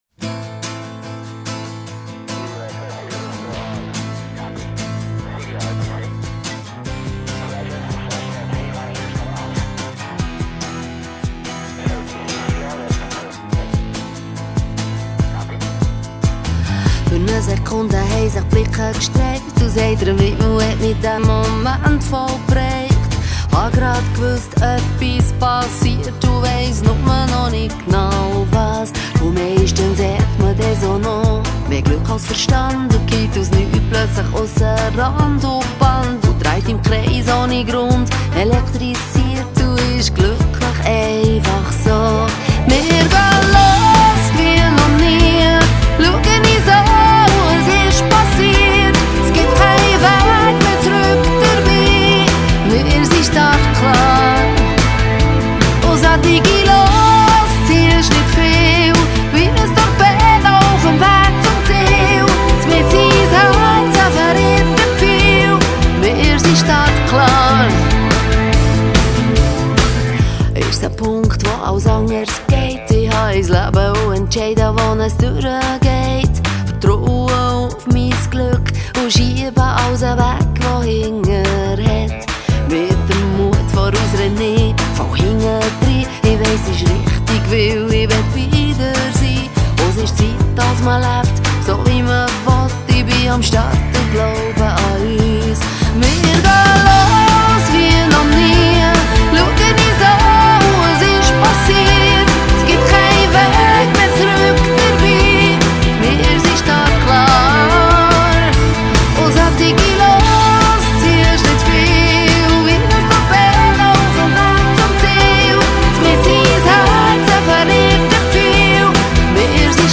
Dialect rock.
female singer